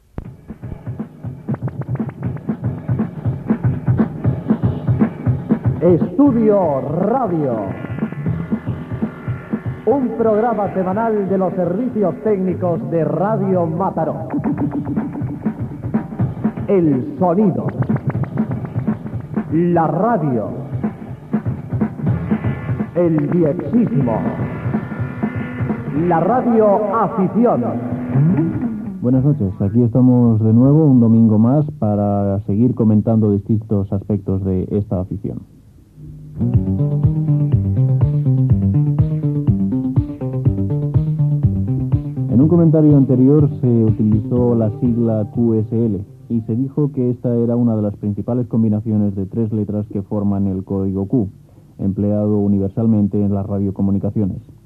Careta i inici del programa dedicat al DXisme tot parlant de les QSL's (targetes de verificació)
Divulgació